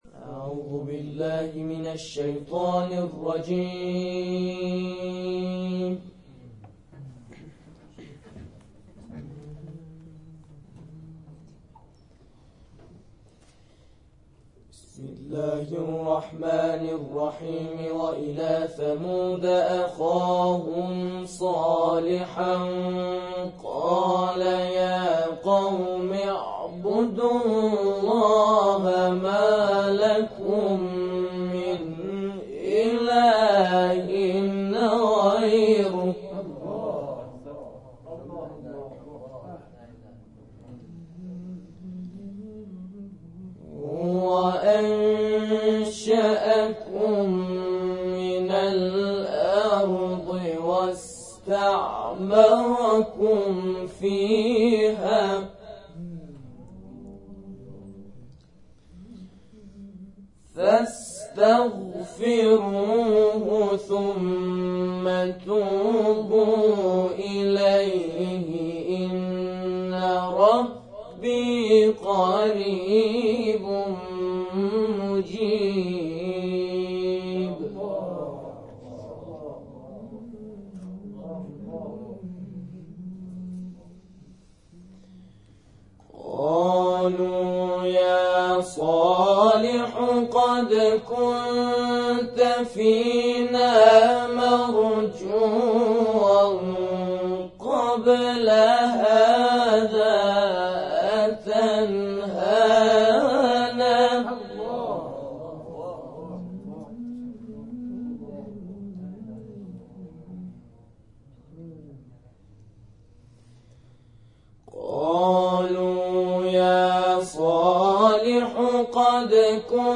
در ادامه جلسه گروه همخوانی سبیل‌الرشاد دقایقی آیاتی از سوره اسراء را به سبک استاد رفعت تلاوت کردند و مورد تشویق حاضران در جلسه قرار گرفتند.
قاریان نوجوان
در ادامه همخوانی گروه سبیل الرشاد ارائه می‌شود.